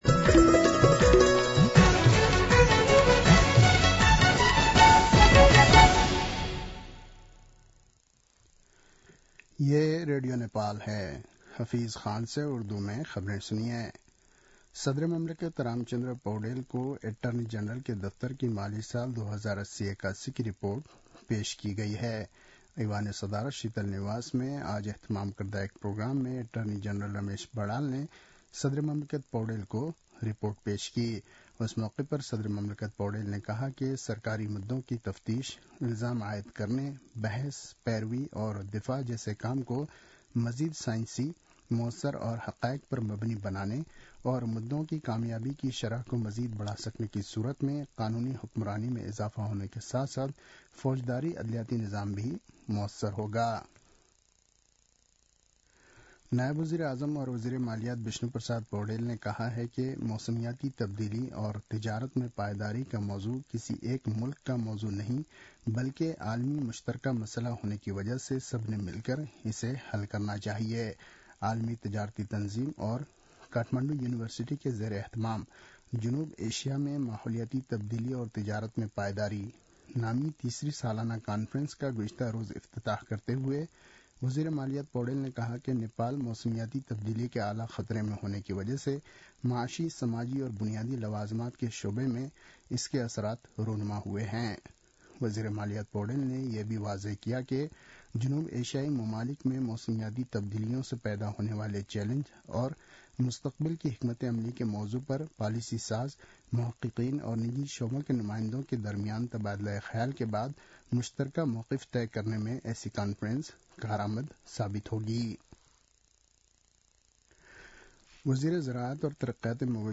उर्दु भाषामा समाचार : २९ पुष , २०८१
Urdu-News-1-1.mp3